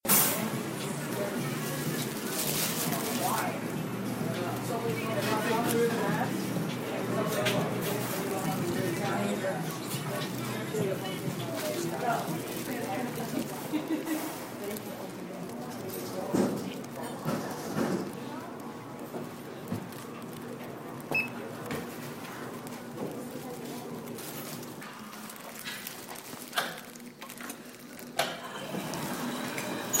Date/Location: 03-16-17, 6:45pm, Estabrook elevator and lobby
Sounds heard: beeping of elevator going down, me sniffle, elevator door open, me walking, me going through turnstile, me opening the door to the outside